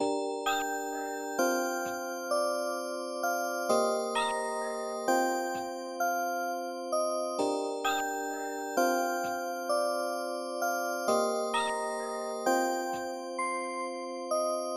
Tag: 132 bpm Trap Loops Guitar Acoustic Loops 2.48 MB wav Key : G